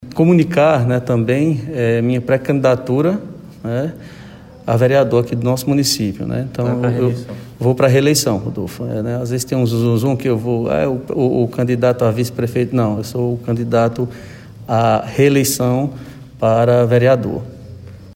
Durante a reunião da Câmara de Vereadores de Sumé realizada ontem (28)